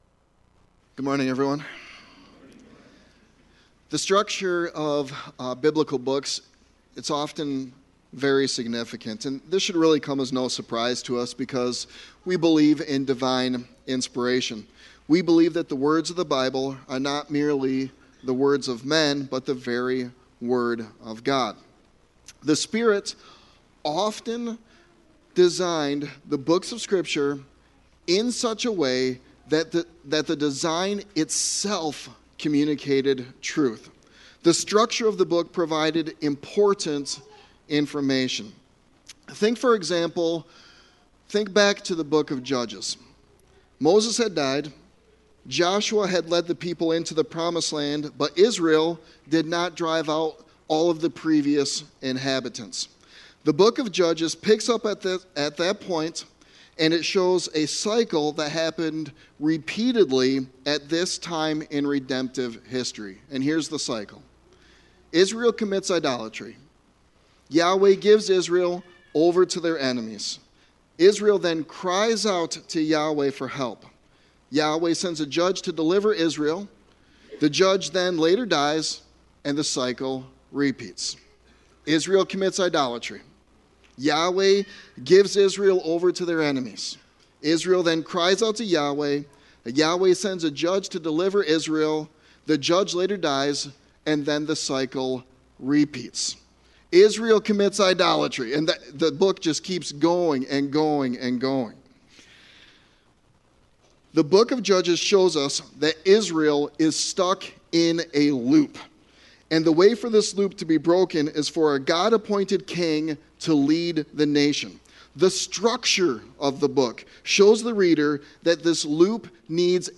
Walk Worthy – Reformed Baptist Church of Kalamazoo
Sermon